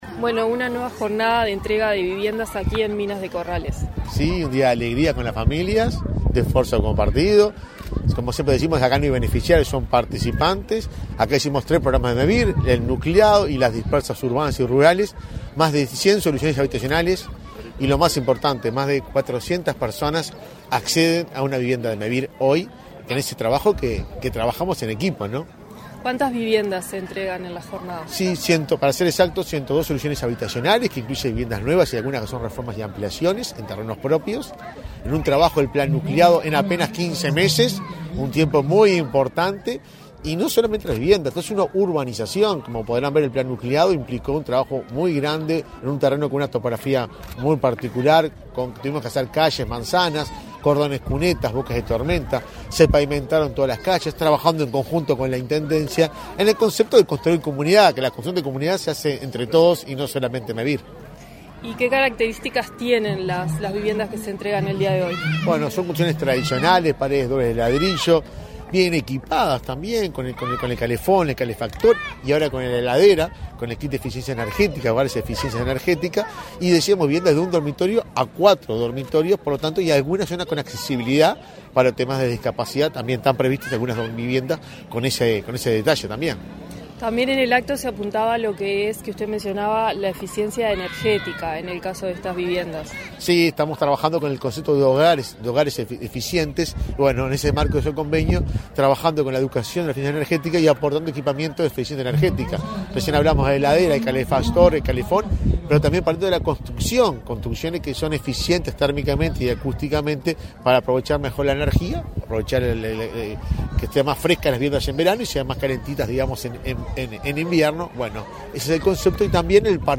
Entrevista al presidente de Mevir, Juan Pablo Delgado
Entrevista al presidente de Mevir, Juan Pablo Delgado 19/10/2023 Compartir Facebook X Copiar enlace WhatsApp LinkedIn Tras participar en la entrega de 100 soluciones habitacionales en Minas de Corrales, en Rivera, este 19 de octubre, el presidente de Mevir, Juan Pablo Delgado, realizó declaraciones a Comunicación Presidencial.